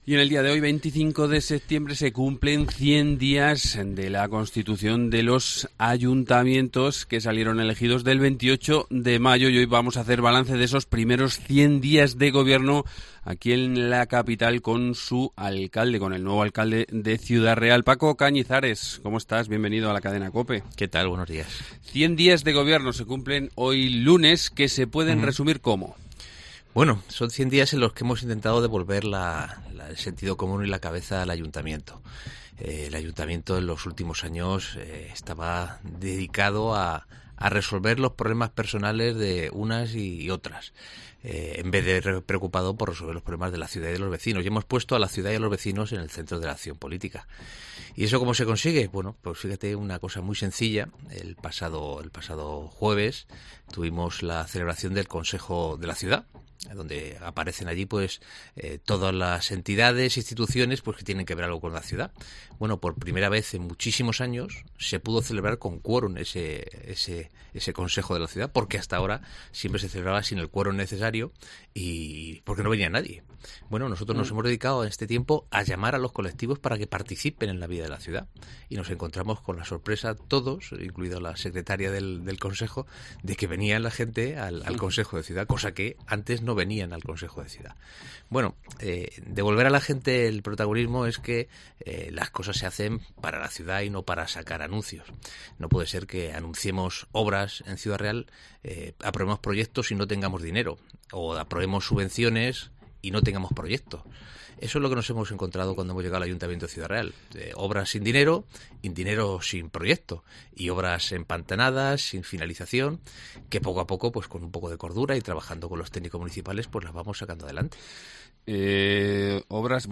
Paco Cañizares, alcalde de Ciudad Real
En una entrevista en Cope, Cañizares ha manifestado que no puede ser que se anuncien obras, como se hizo antaño, sin tener dinero para ejecutarlas.